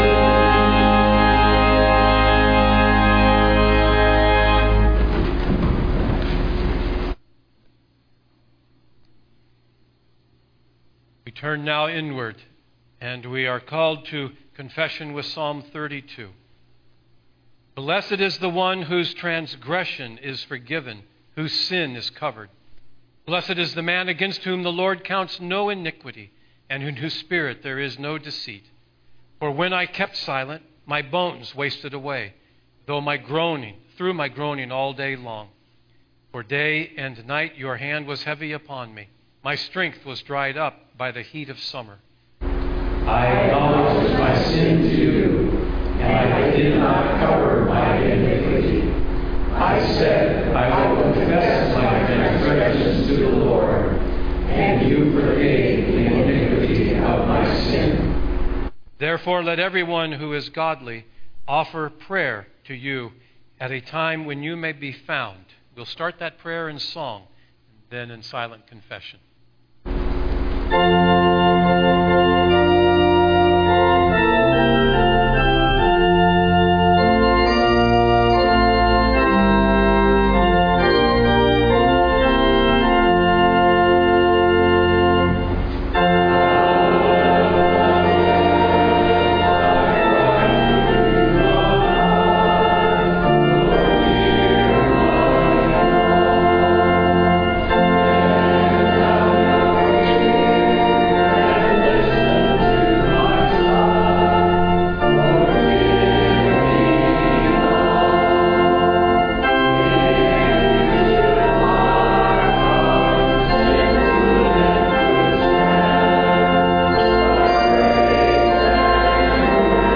Prayer Service – First CRC Lynden